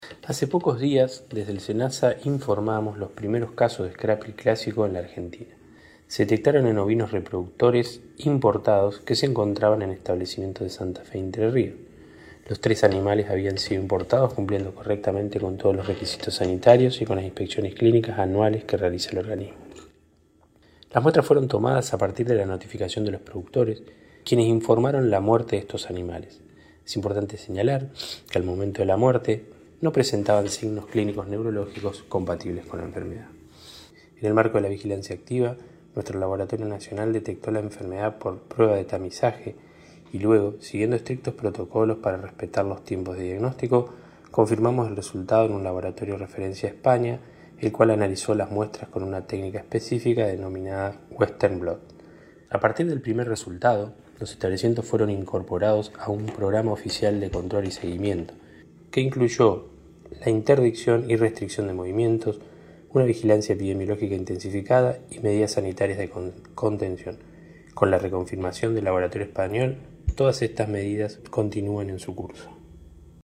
En declaraciones distribuidas a la prensa, funcionario nacional describió la enfermedad e hizo referencia a la detección de casos y a las medidas adoptadas por SENASA para su prevención y control.